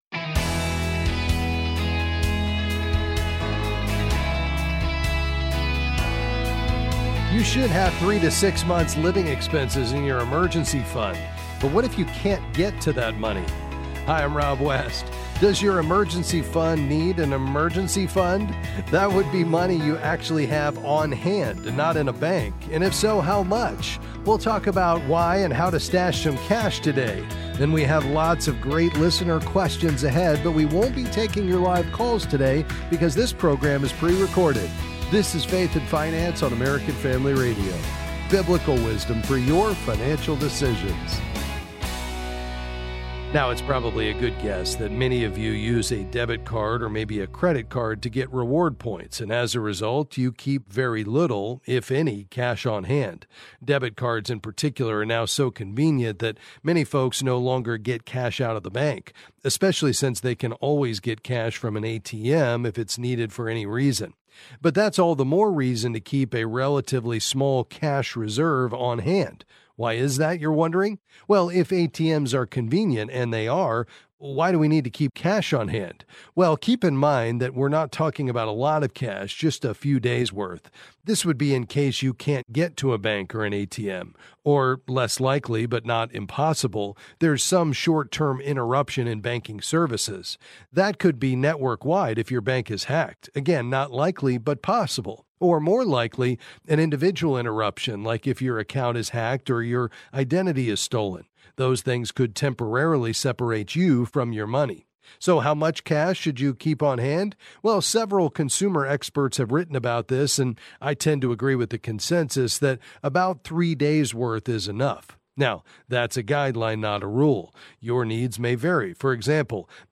Then he’ll answer questions on various financial topics.